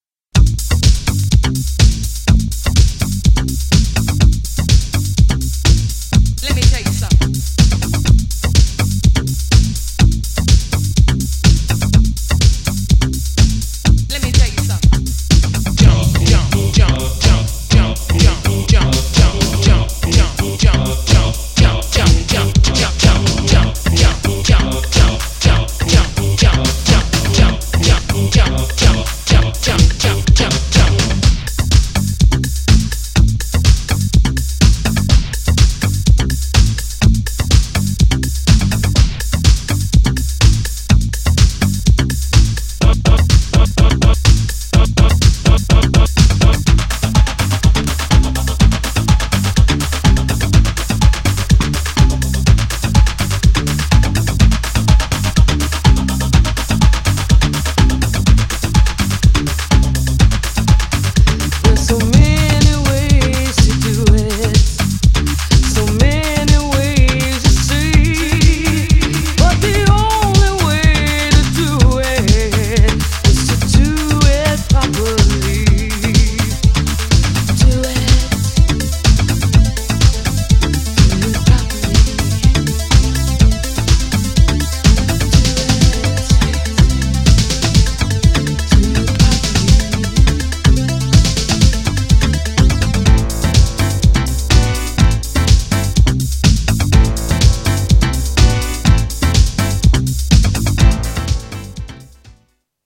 GENRE House
BPM 121〜125BPM